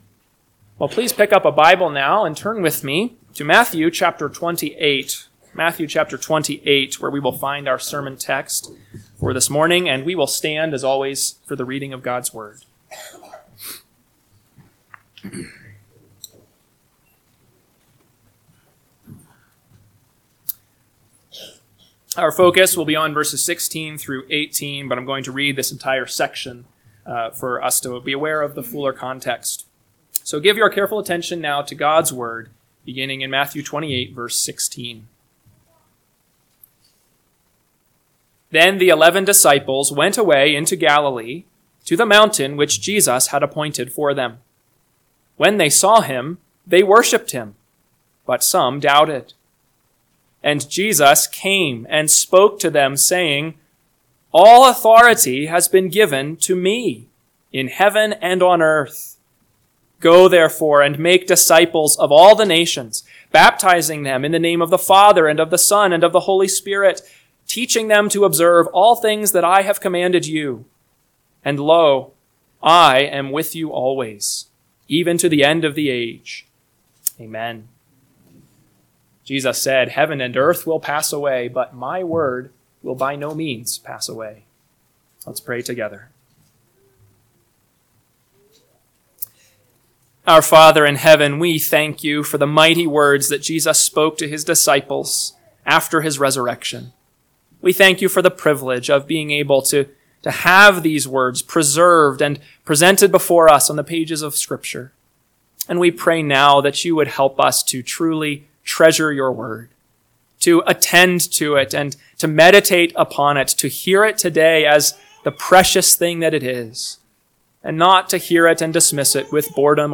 AM Sermon – 6/1/2025 – Matthew 28:16-18 – Northwoods Sermons